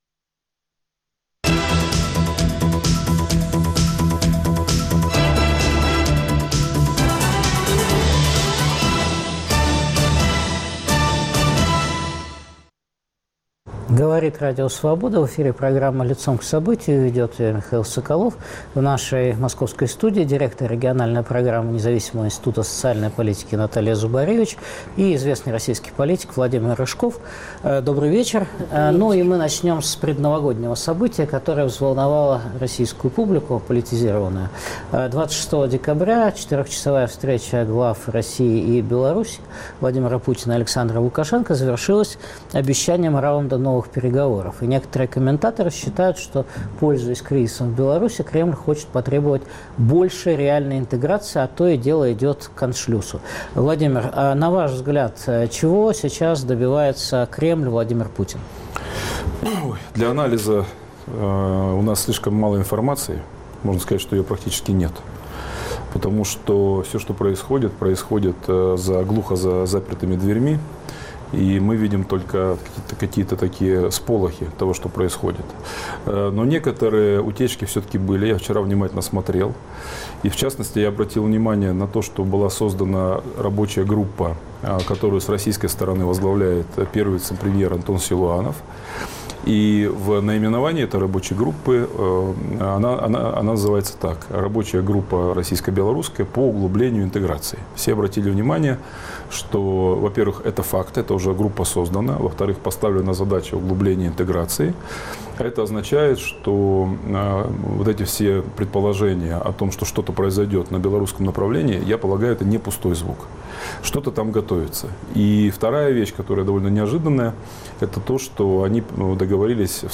Давление Кремля на Беларусь, расходы центра на регионы, стоимость содержания сателлитов Кремля, социально-экономические итоги 2018 года обсуждают директор региональной программы Независимого института социальной политики Наталья Зубаревич и политик Владимир Рыжков.